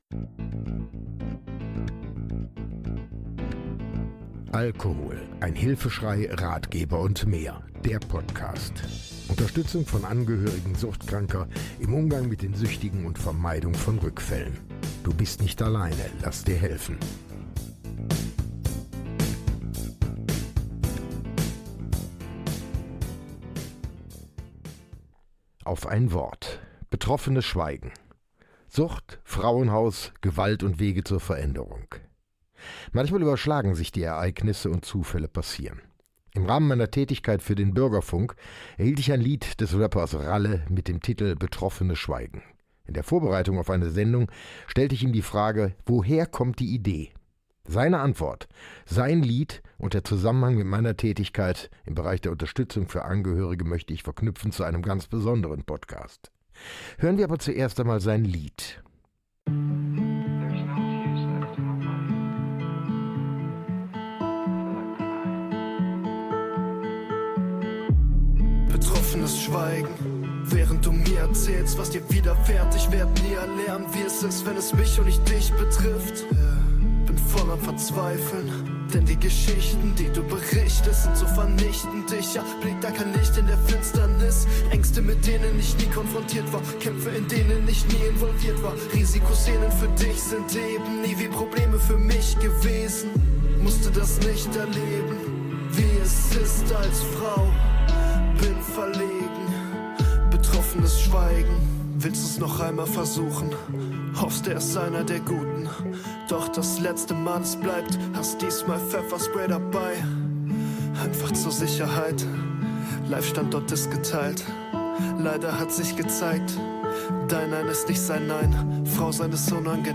Rap-Song